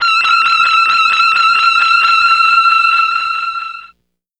Index of /90_sSampleCDs/Zero-G - Total Drum Bass/Instruments - 2/track43 (Guitars)
03 Smoke Alarm E.wav